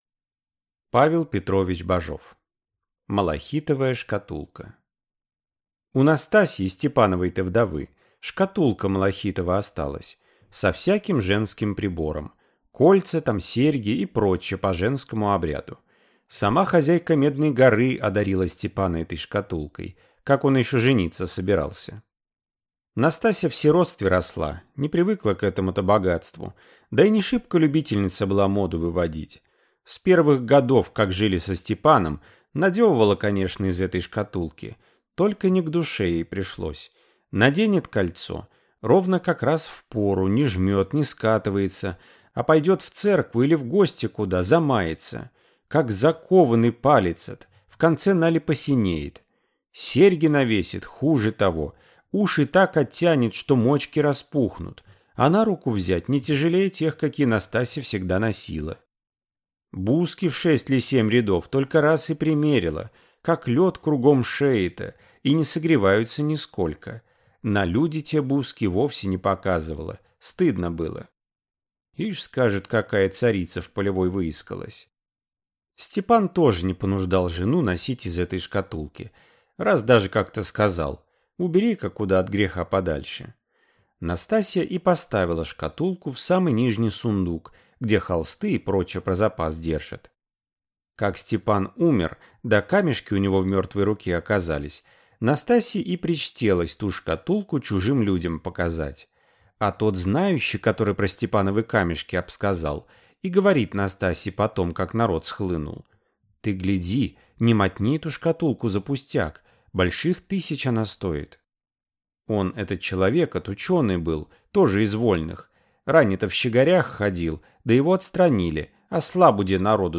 Аудиокнига Малахитовая шкатулка | Библиотека аудиокниг